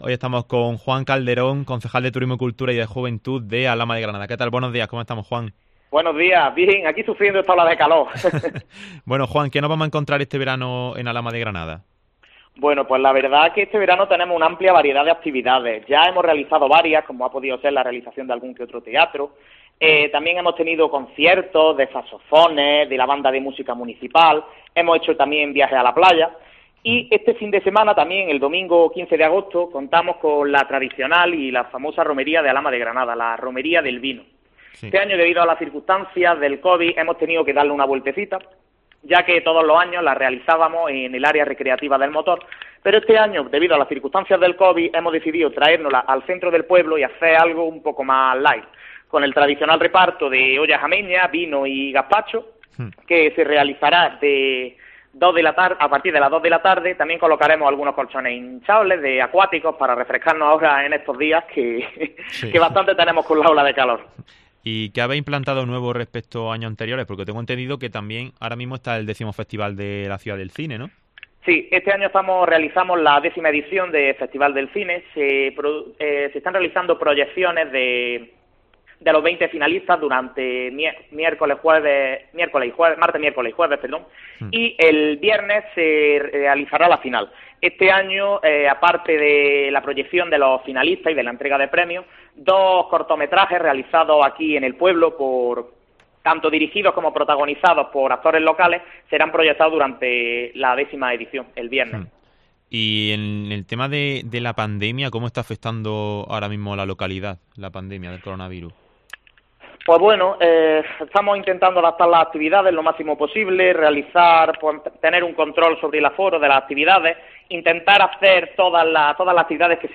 En COPE, hemos hablado con el Concejal de Turismo y Juventud, Juan Calderón.